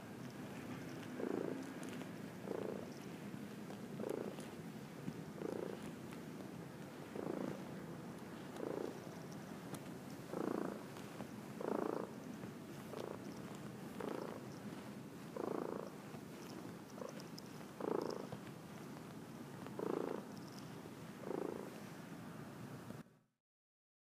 猫のゴロゴロw← うちの猫www